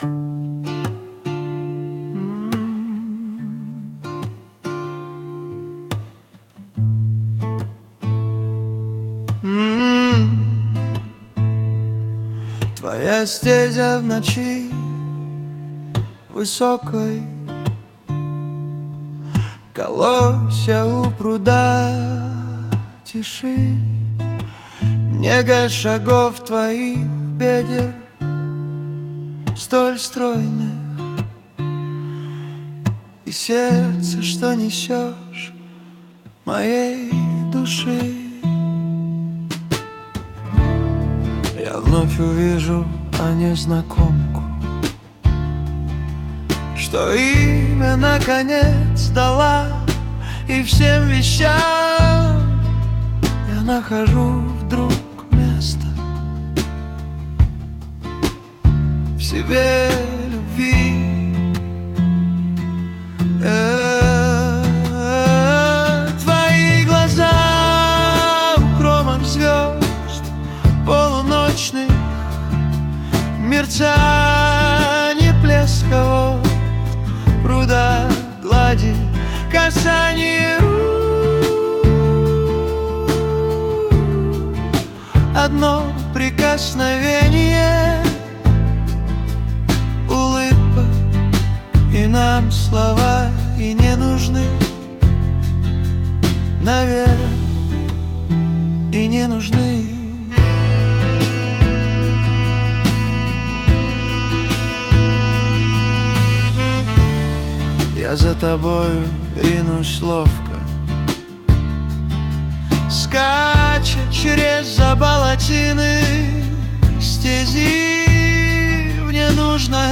Музыка и исполнение принадлежит ИИ.
ТИП: Пісня
СТИЛЬОВІ ЖАНРИ: Романтичний